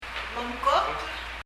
momkokl　　[mɔmkɔkl]　　　早起きする　　get up early
発音